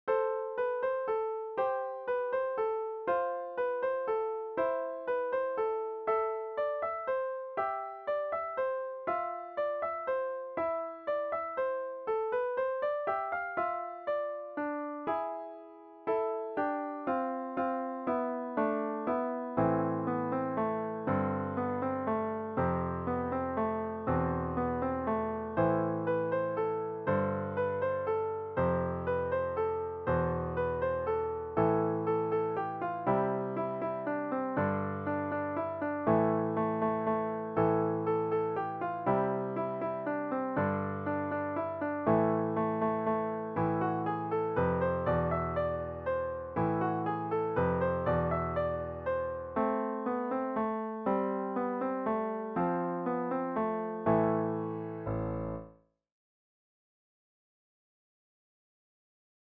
Produced digitally in Finale music notation software